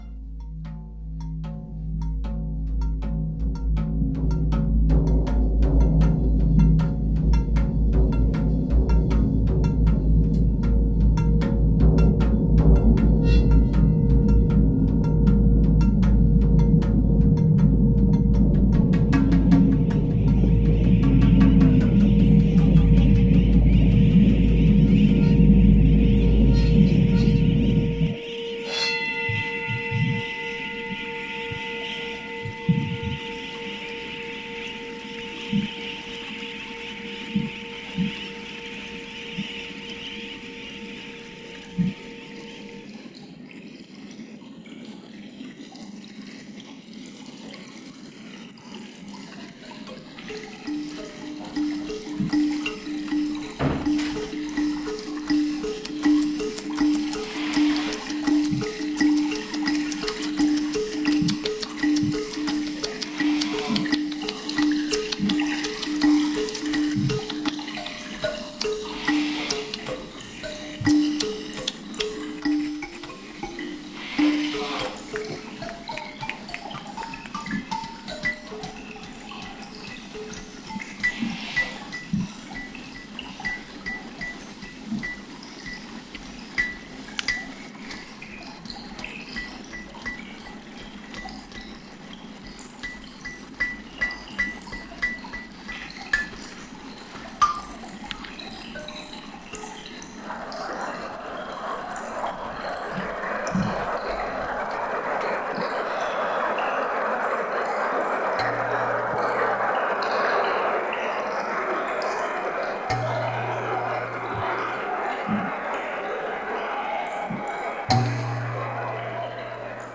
sonic collage,  peruse a gallery of visual harvests from our time together (below).
Closing-Ceremony-1.m4a